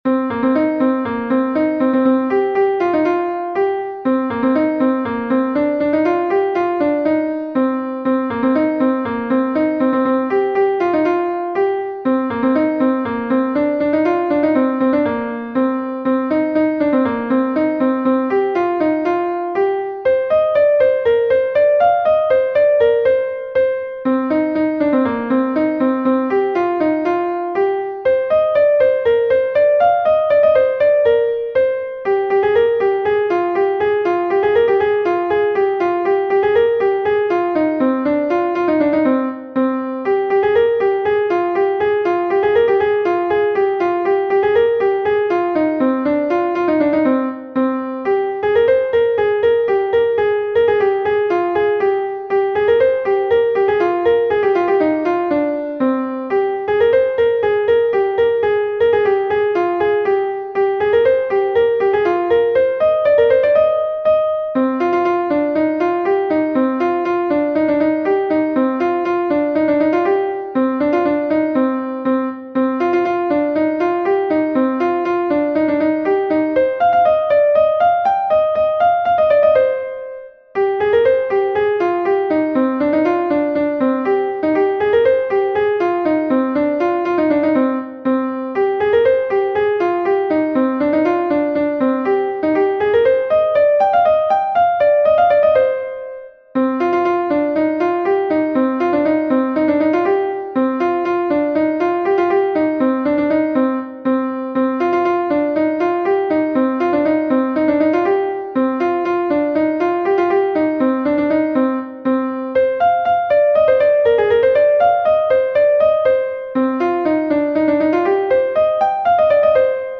Gavotenn Lokmaleù Bro-Bourled is a Gavotte from Brittany